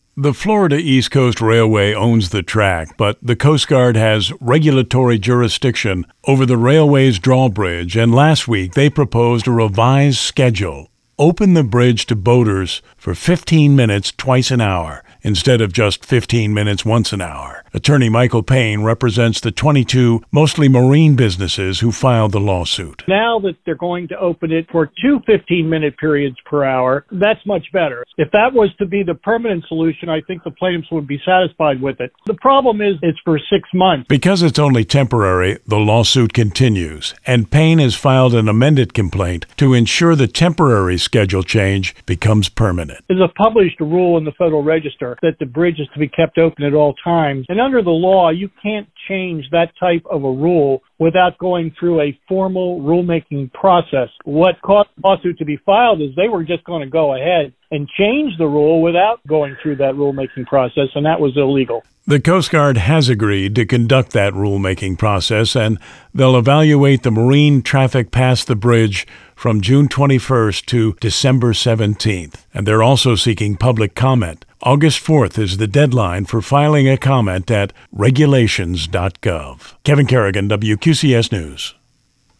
was a guest on WQCS